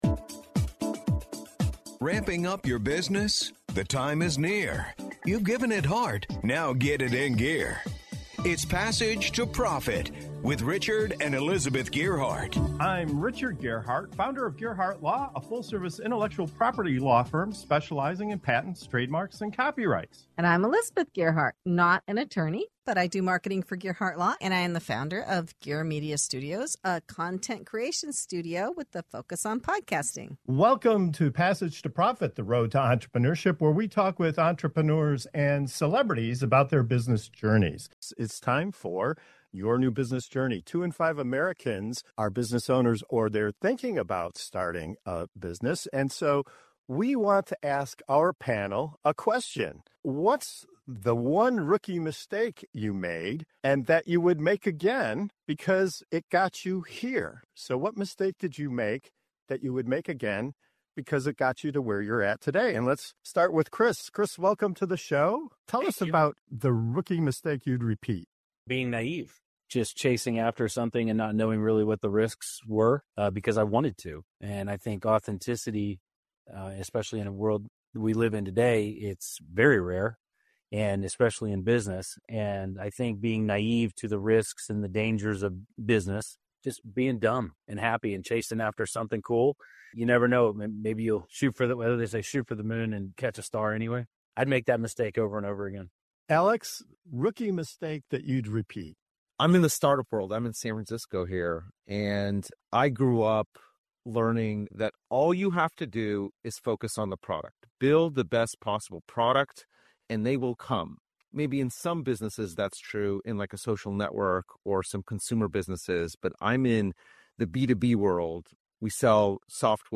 In this segment of "Your New Business Journey" on Passage to Profit Show, our panel of entrepreneurs and business leaders open up about the “rookie mistakes” they’d gladly make again—because those missteps shaped their journeys. From chasing dreams with fearless naivety, to over-investing in product before realizing the power of marketing, to learning the hard truth that the right partners can make or break a venture, each story reveals how failure can be the foundation of success.